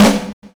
Snare set 2 001.wav